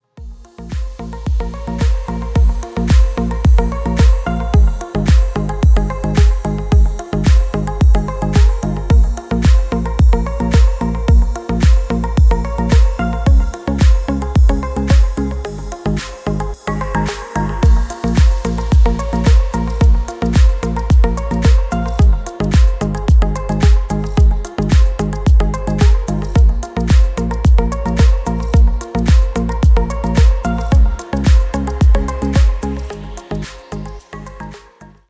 • Качество: 320, Stereo
deep house
атмосферные
Electronic
спокойные
красивая мелодия